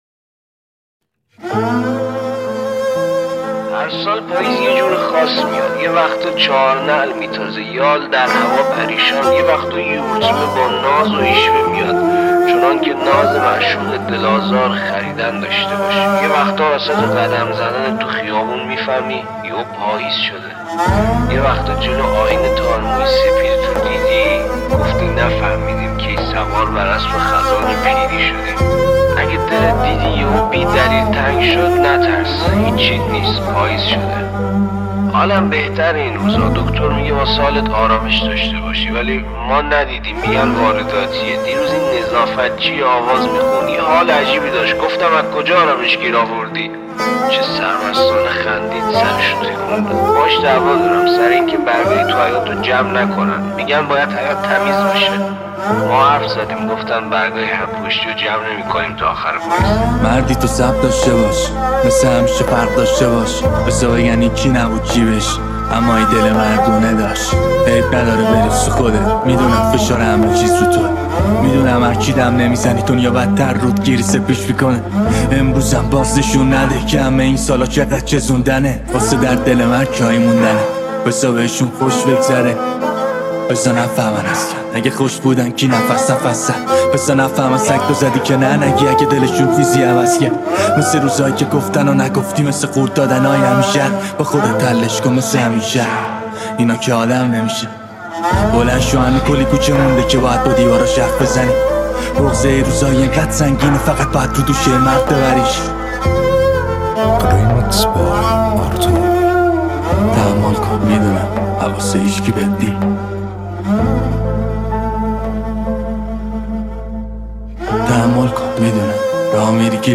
ژانر: رپ